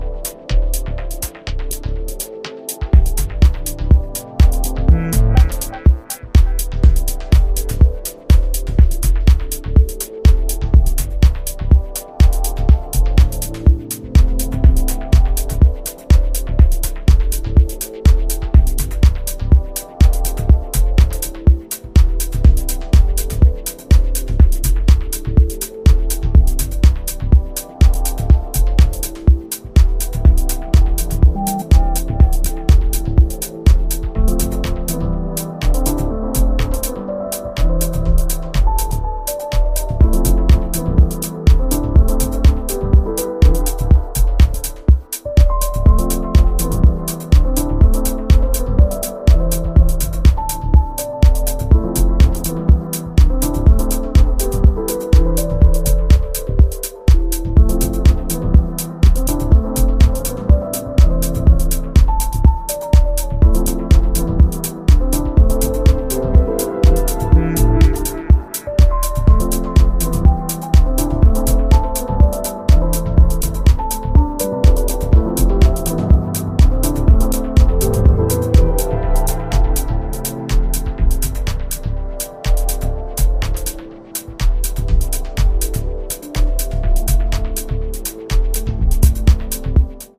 ダビーなシンセパルスとアトモスフェリックなコード、ピアノの旋律が揺蕩う